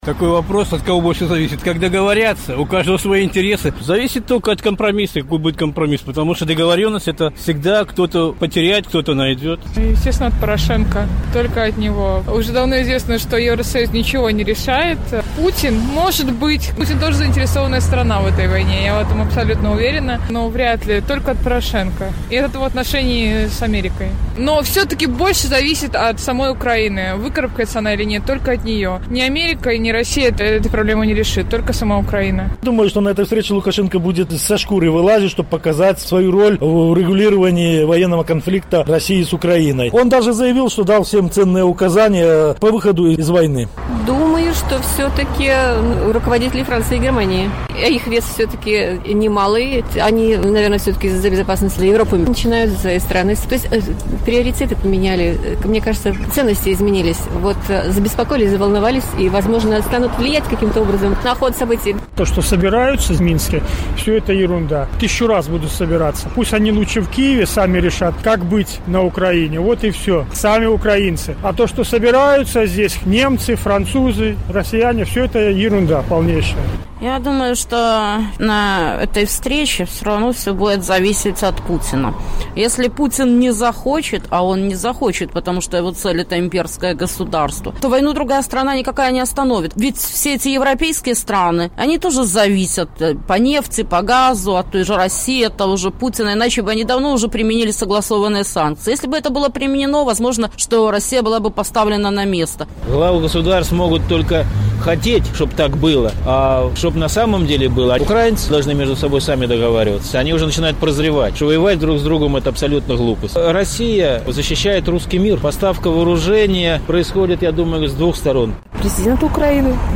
На гэтае пытаньне адказваюць жыхары Гомеля.